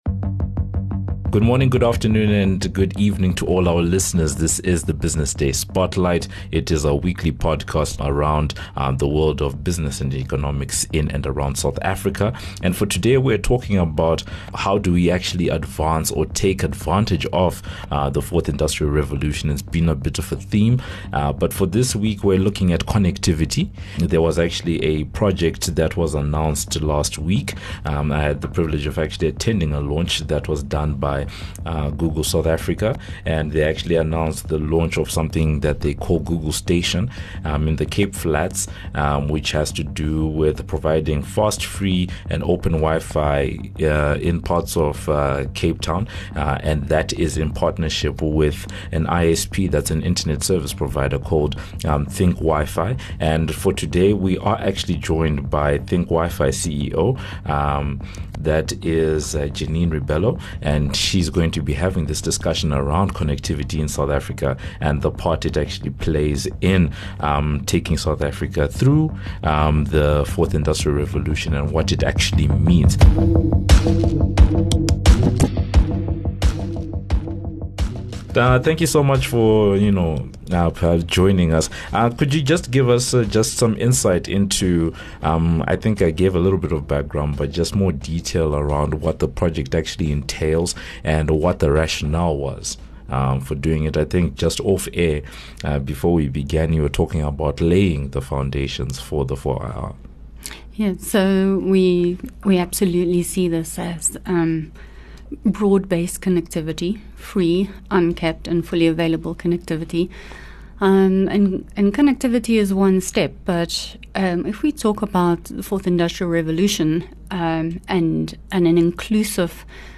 Listen in to hear the full discussion and thoughts around these and other questions.